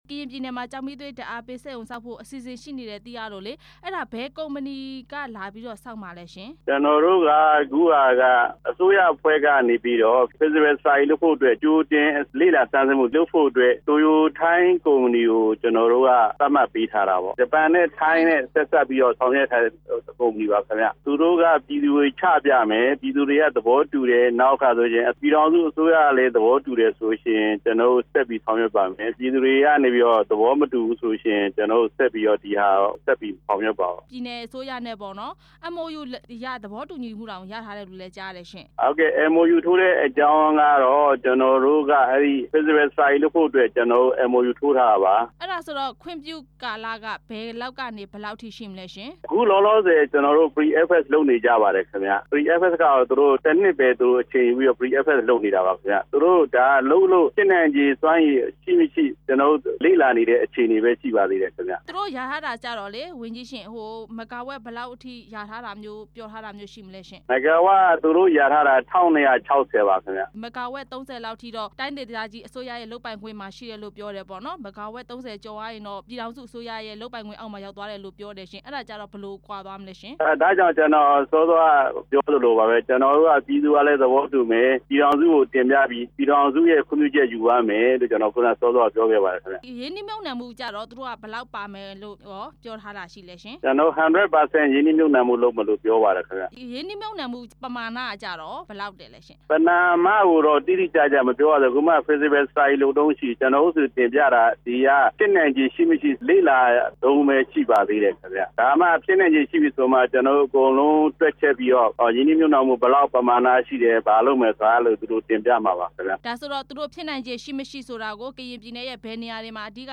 ဘားအံမှာ ကျောက်မီးသွေးစက်ရုံ ဆောက်မယ့်အခြေအနေ မေးမြန်းချက်